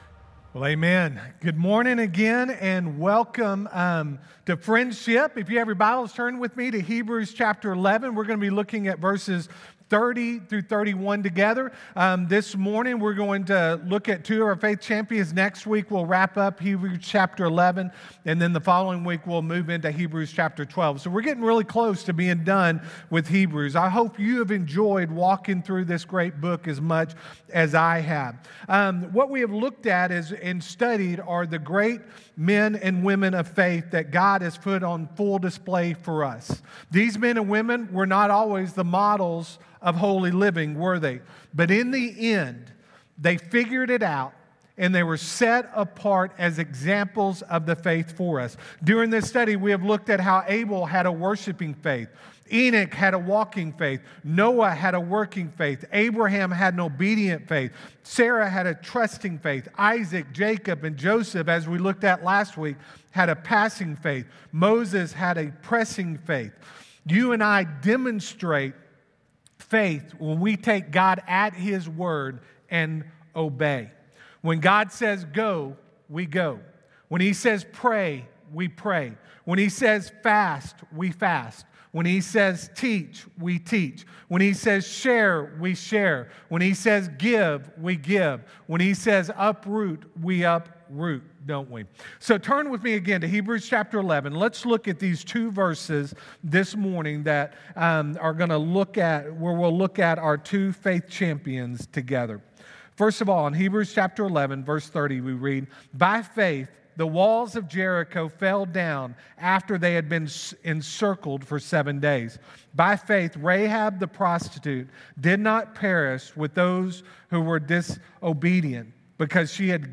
Friendship Baptist Church SERMONS